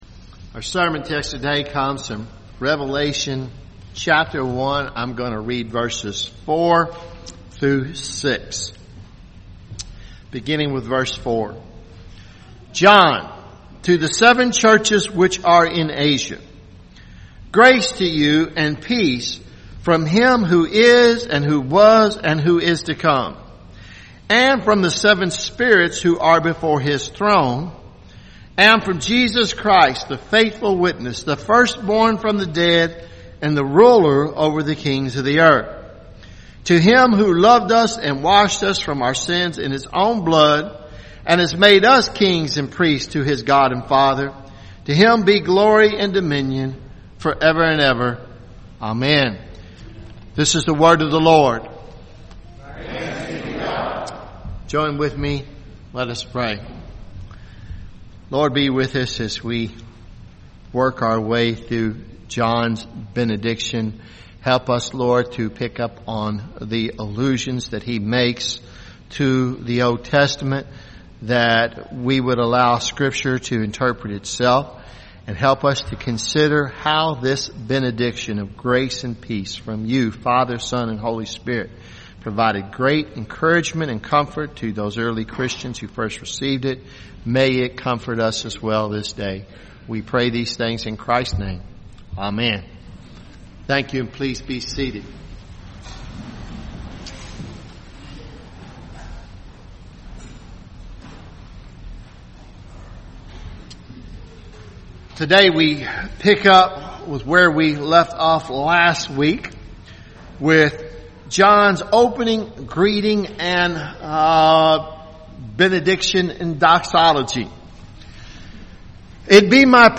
Revelation sermon series , Sermons